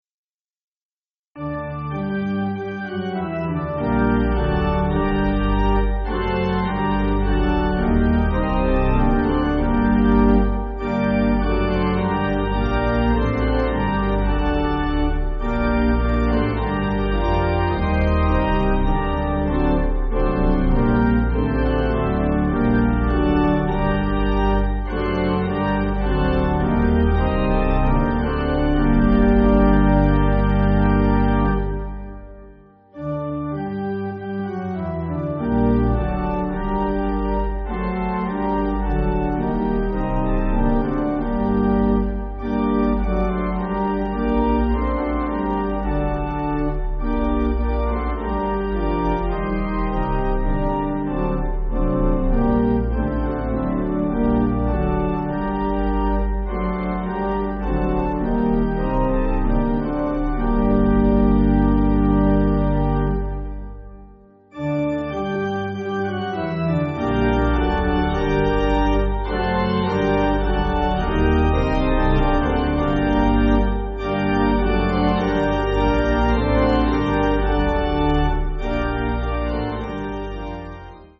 Organ
(CM)   4/Gm